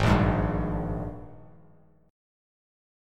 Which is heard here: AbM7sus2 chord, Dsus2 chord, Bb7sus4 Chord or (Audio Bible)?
AbM7sus2 chord